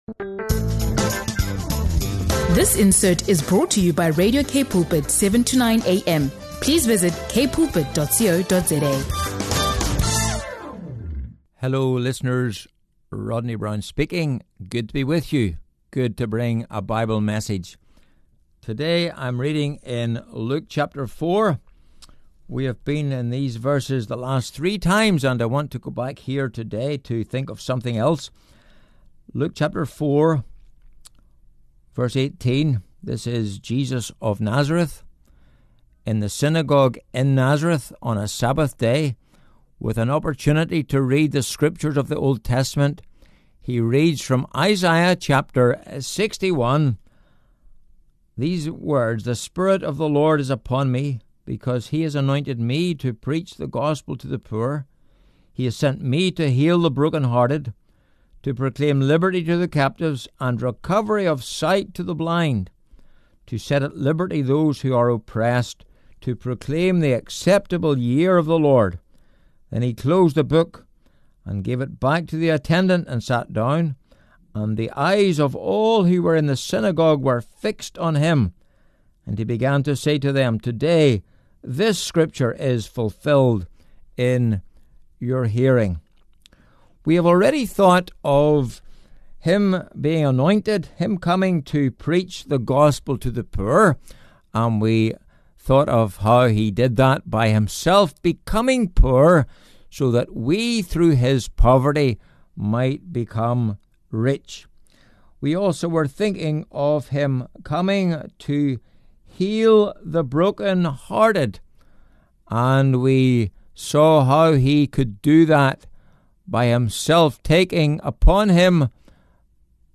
Jesus the Light of the World | Luke 4:18 Bible Message on Healing, Freedom & Salvation